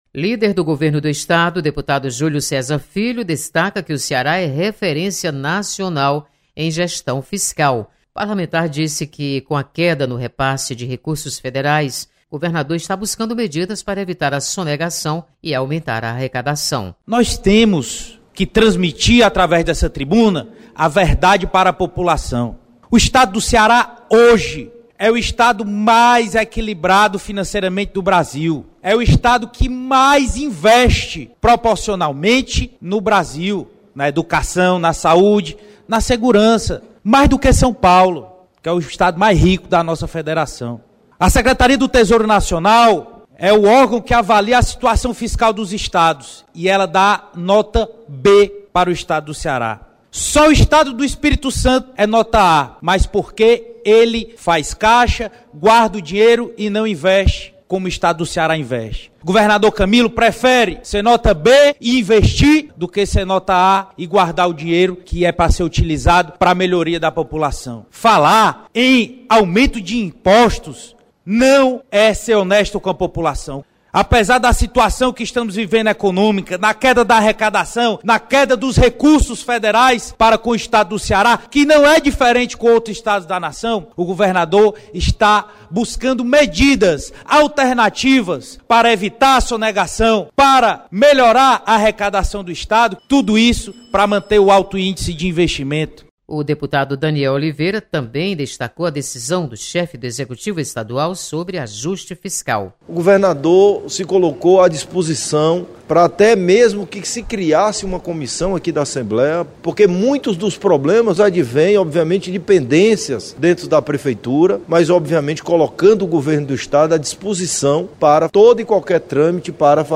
Deputados comentam sobre novas medidas do Governo do Estado. (3'49")